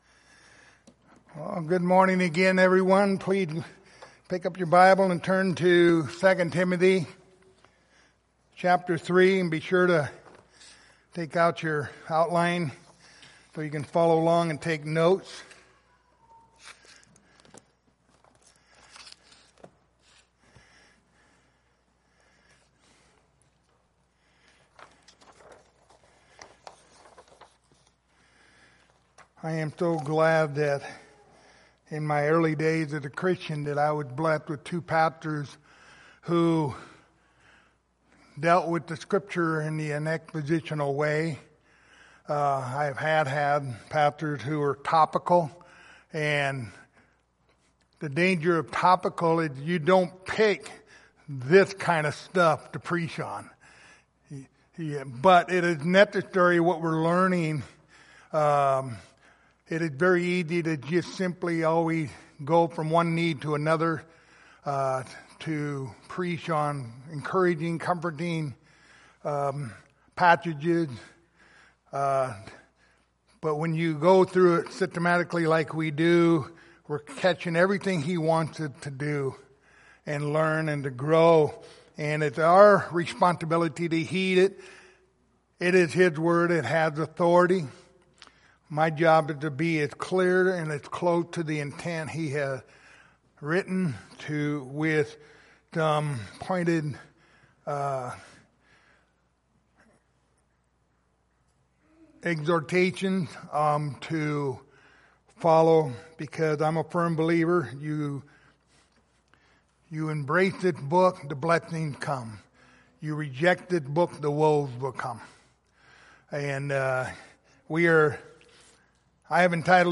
Passage: 2 Timothy 3:6-9 Service Type: Sunday Morning